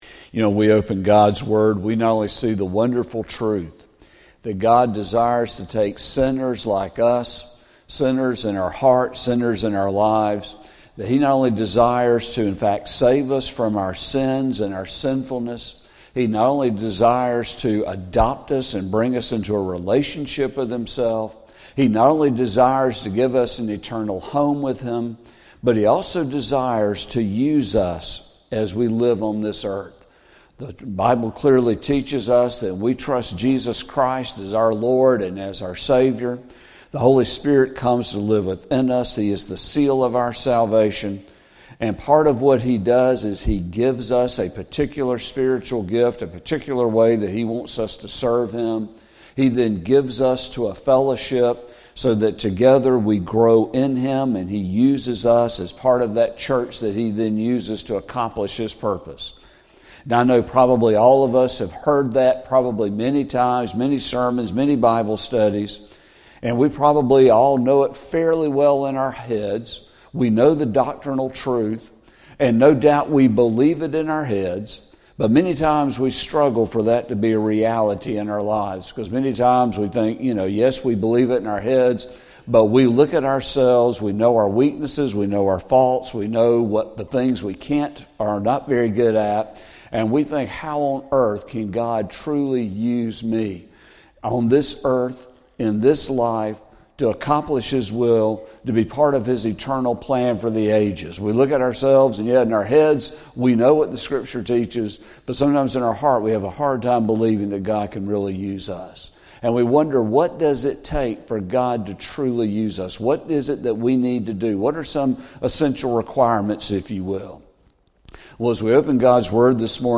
Sermon | January 5, 2024